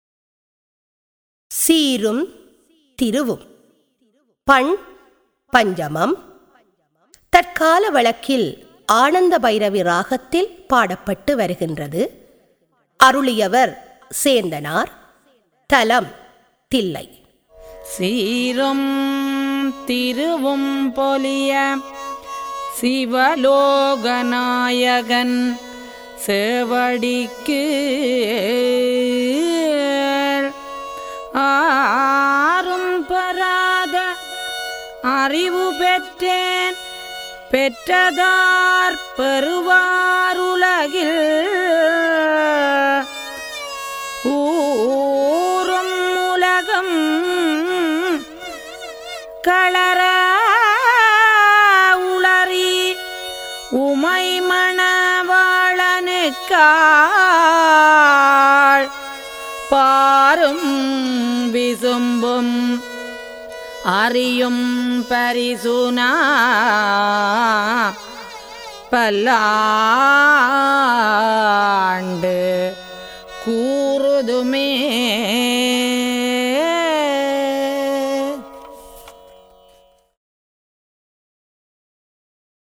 தரம் 6 இல் கல்வி பயிலும் சைவநெறிப் பாடத்தை கற்கும் மணவர்களின் நன்மை கருதி அவர்கள் தேவாரங்களை இலகுவாக மனனம் செய்யும் நோக்கில் இசைவடிவாக்கம் செய்யப்பட்ட தேவாரப்பாடல்கள் இங்கே பதிவிடபட்டுள்ளன.